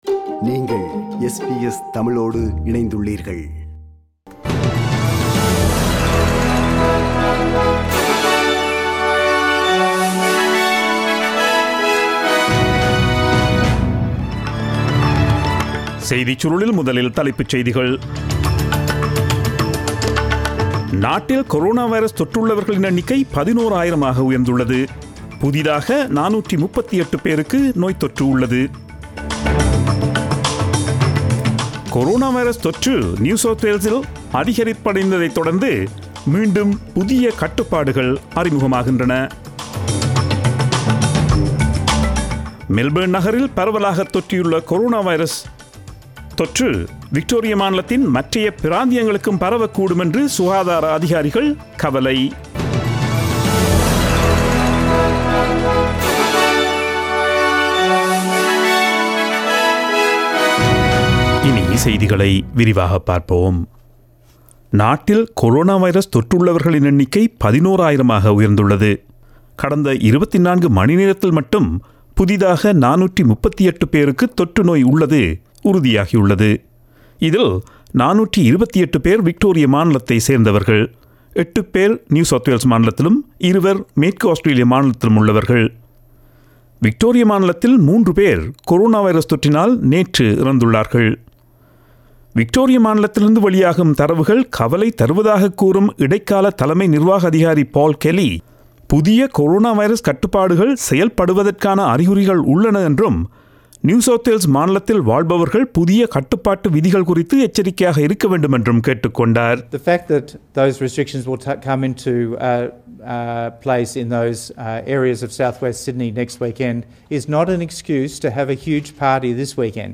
Australian news bulletin aired on Friday 17 July 2020 at 8pm.